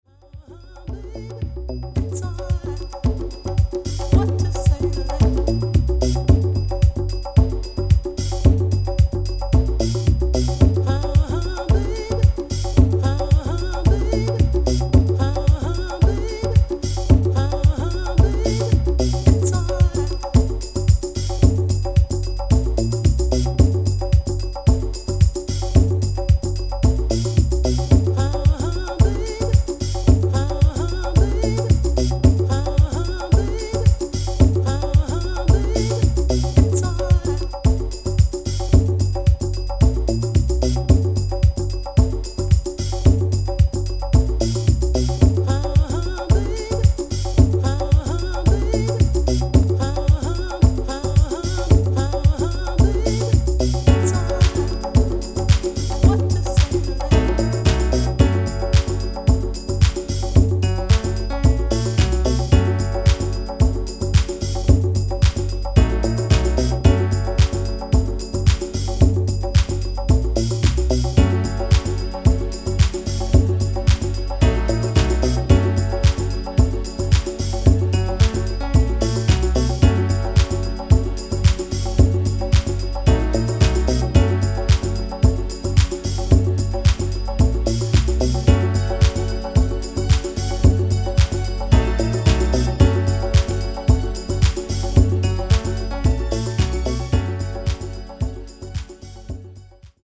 ムーディーでアンダーグランドな傑作ディープ・ハウスを計4トラック収録！
[90SHOUSE]